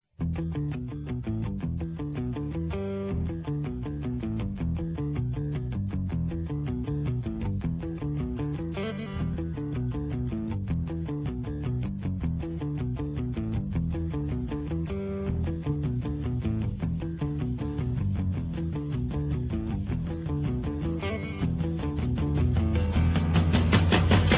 Rock Intros: